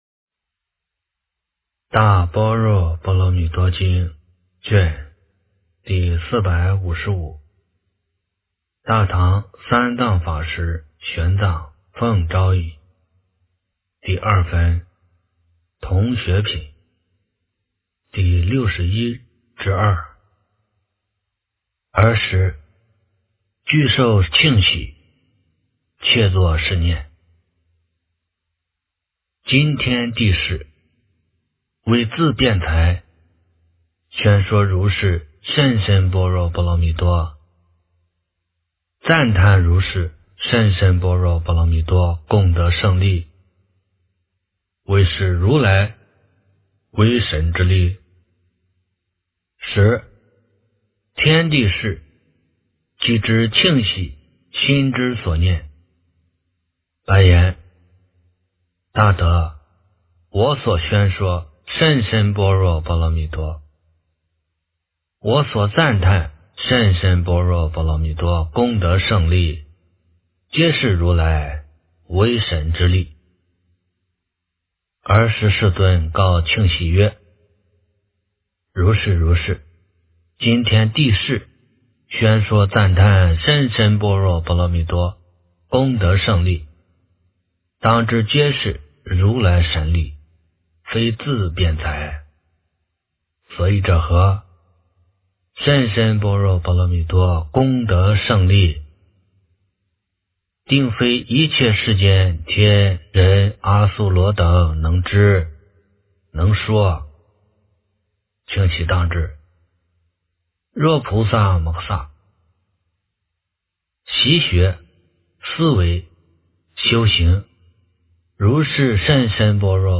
大般若波罗蜜多经第455卷 - 诵经 - 云佛论坛